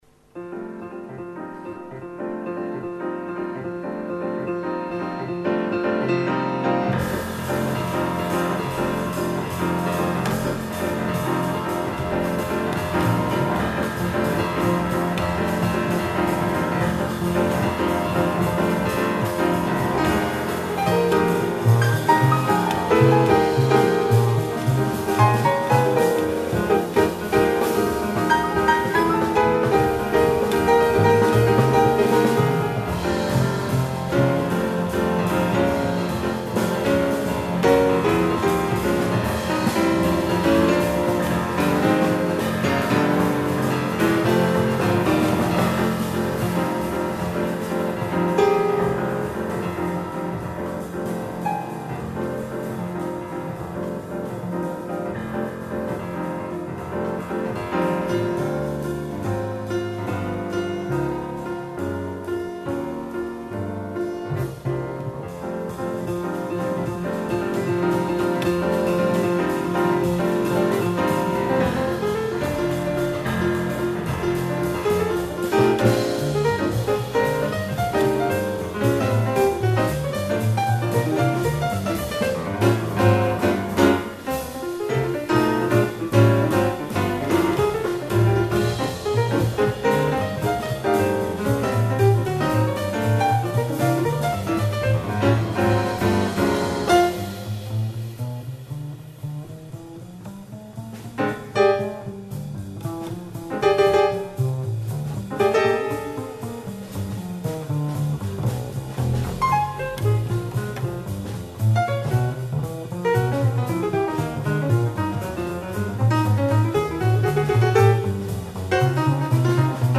violon
piano
contrebasse
batterie